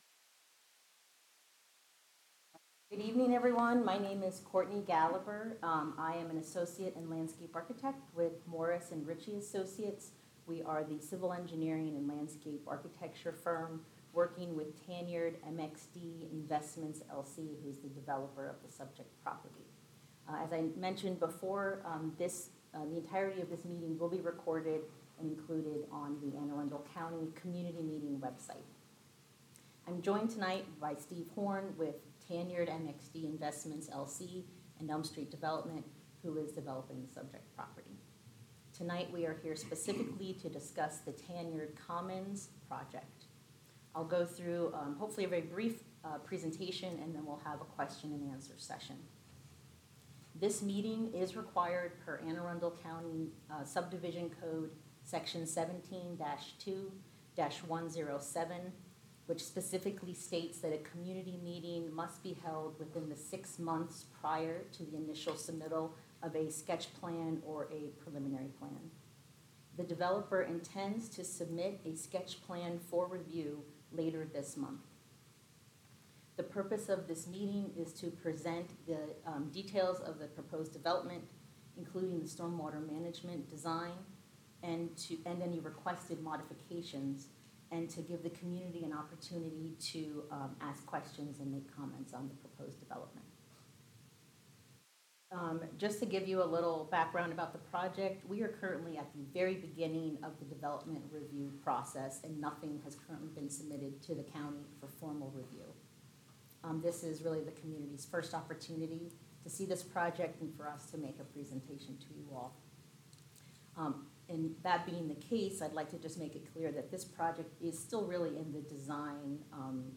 Tanyard Commons Pre-submittal Community Meeting | Morris & Ritchie Associates, Inc.